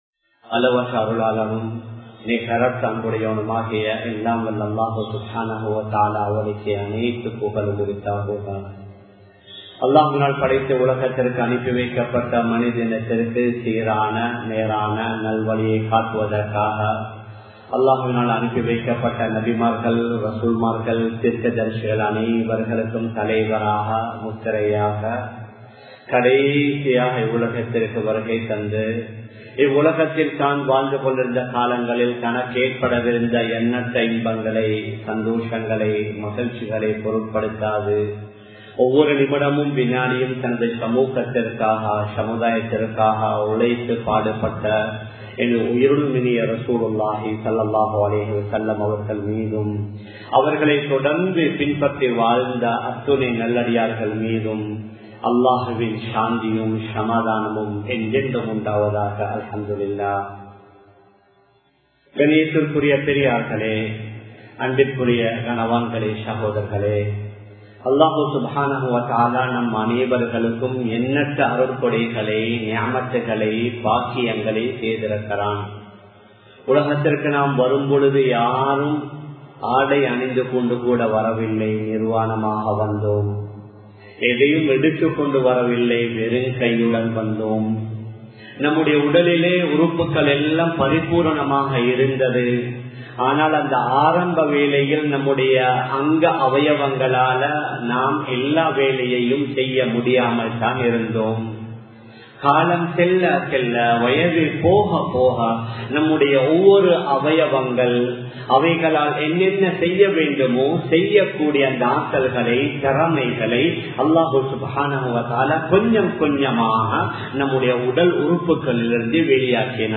இஸ்லாமிய பார்வையில் வாக்குரிமை (Voting Rights in Islamic Perspective) | Audio Bayans | All Ceylon Muslim Youth Community | Addalaichenai
Samman Kottu Jumua Masjith (Red Masjith)